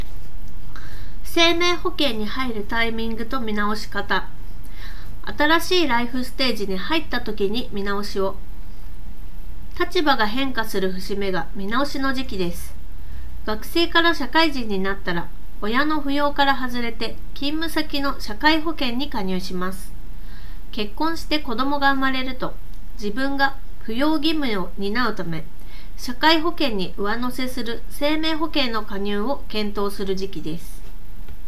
周囲の環境音(空調ノイズ)まで拾われているものの、普通に通話するには十分に優れたマイク性能となっている。話者の発言内容がクリアに拾い上げられており、非常に聴き取りやすい。
▼ACEMAGIC LX15PROの内蔵マイクで拾った音声単体